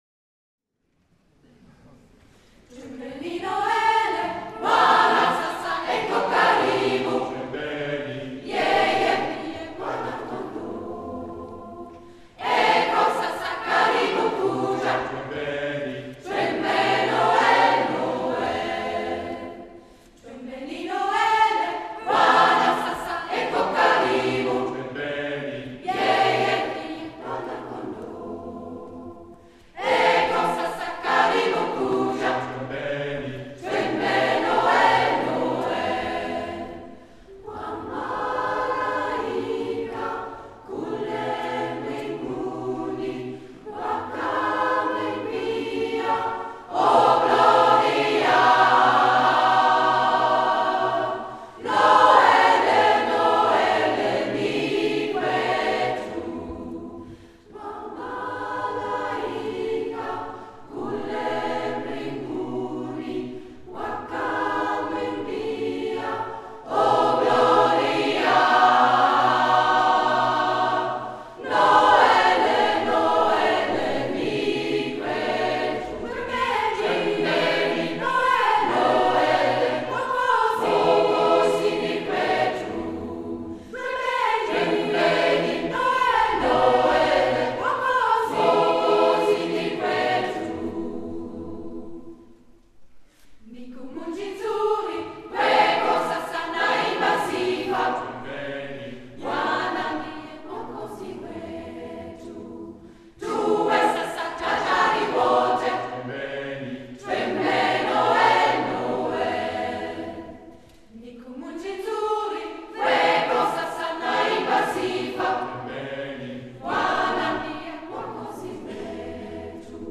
Hörproben Hier finden Sie Beispielaufnahmen aus verschiedenen Bereichen: Xylophonspiel Musikprojekt von Schülerinnen und Schülern der Realschule Henstedt-Ulzburg Gospelchor Kiel-Holtenau Afrikanischer Gospel
Gospelchor_Holtenau.mp3